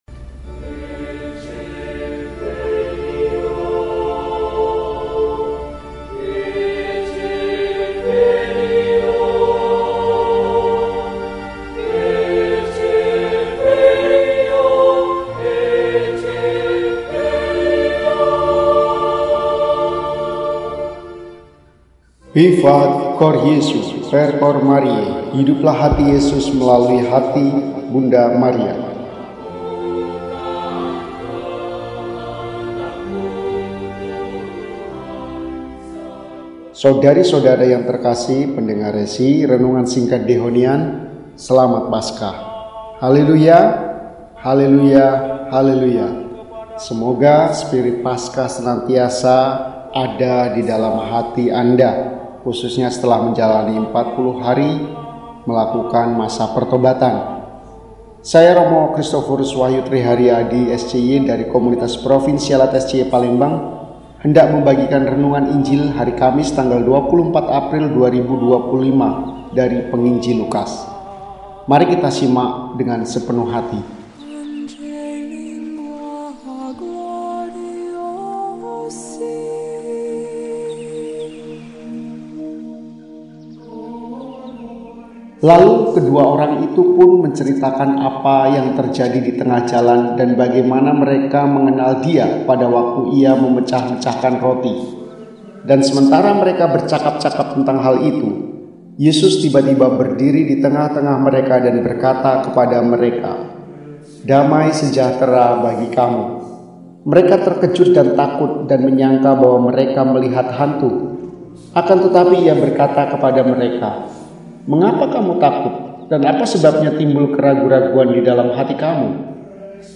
Kamis, 24 April 2025 – Hari Kamis dalam Oktaf Paskah – RESI (Renungan Singkat) DEHONIAN